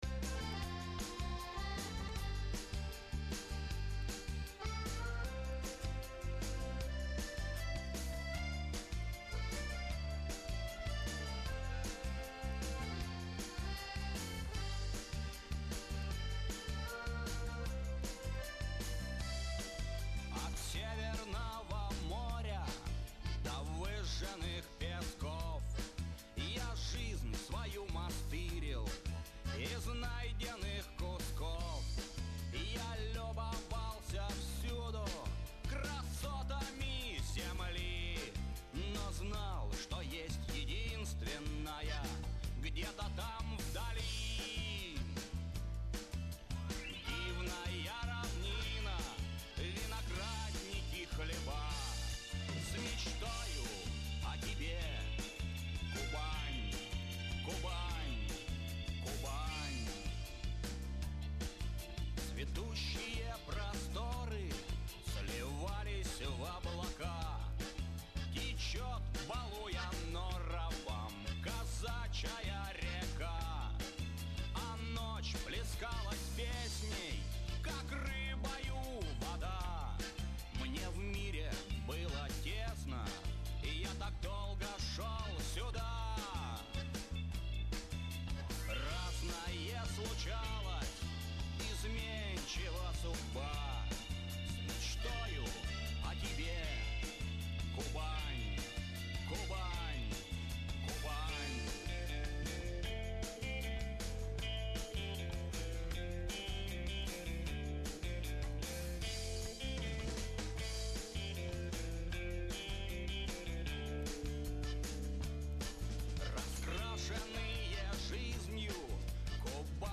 А там сельскохозяйственная романтика, с элементами рока.
Да ещё мотивчик какой то знакомый.